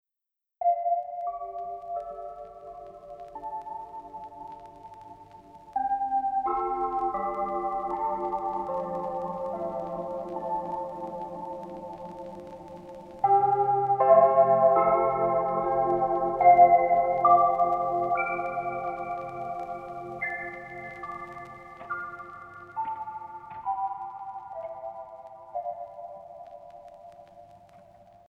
Die wichtigste Parameter für die Klanggestaltung sind das mischbaren Pickup-Direktsignal und die Mikrofonabnahme sowie Color Shift und Transient. Patina erreicht man über Flutter und Vinyl Noise.